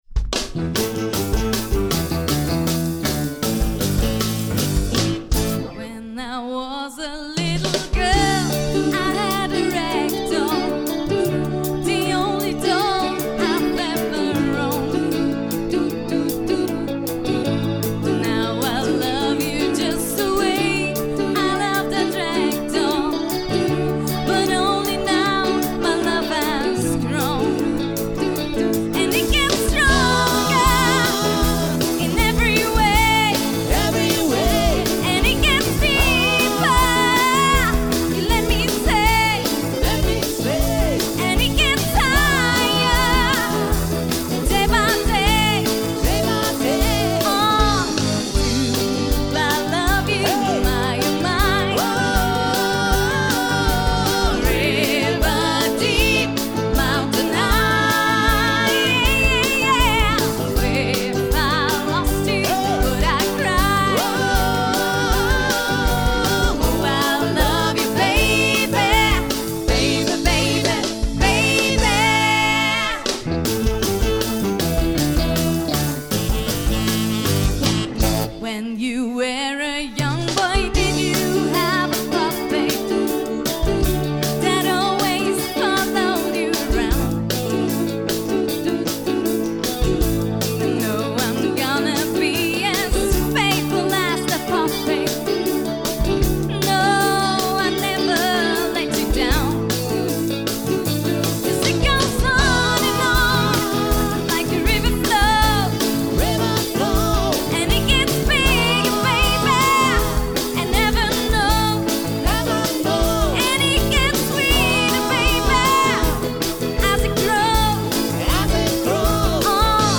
Ladiesnite 2009